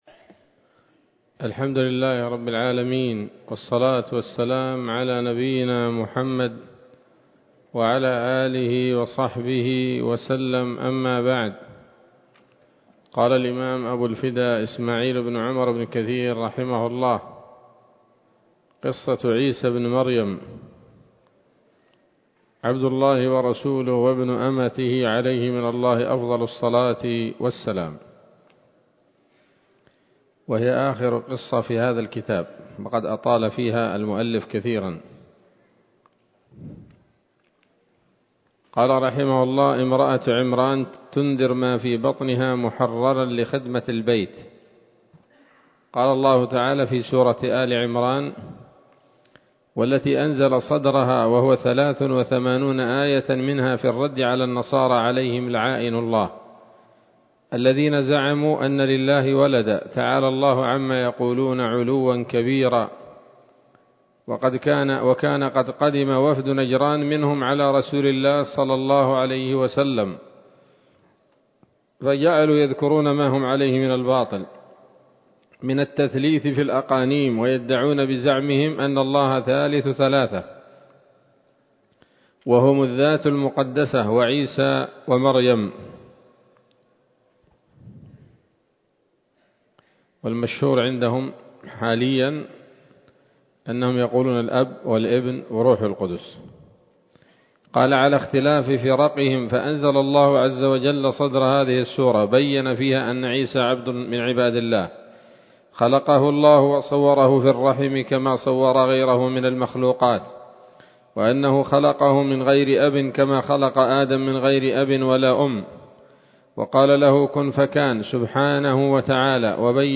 ‌‌الدرس السابع والثلاثون بعد المائة من قصص الأنبياء لابن كثير رحمه الله تعالى